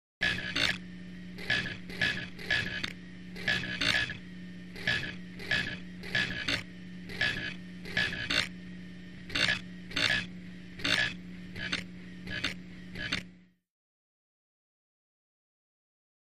Alien Broadcast; Various Clicking Signals.